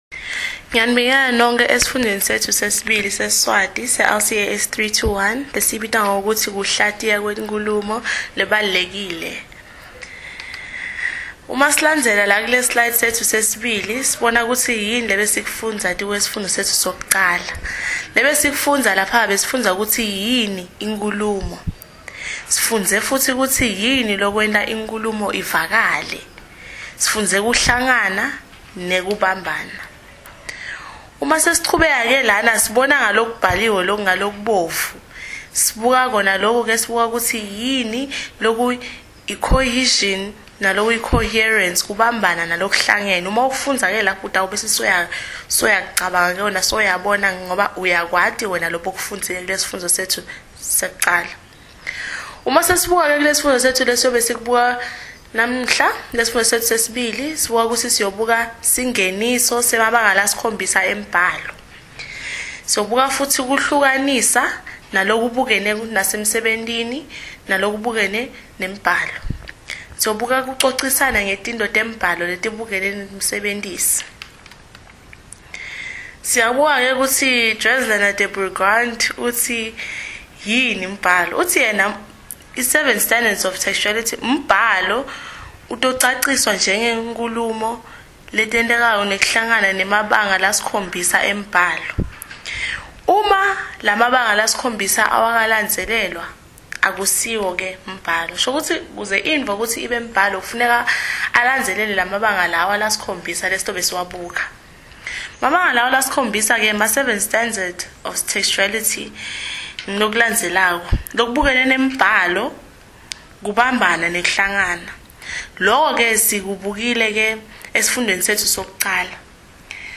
CAT lecture 2 siSwati